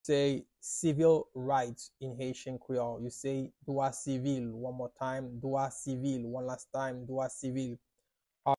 “Civil Rights” in Haitian Creole - “Dwa sivil” pronunciation by a Haitian teacher
“Dwa sivil” Pronunciation in Haitian Creole by a native Haitian can be heard in the audio here or in the video below:
How-to-say-Civil-Rights-in-Haitian-Creole-Dwa-sivil-pronunciation-by-a-Haitian-teacher.mp3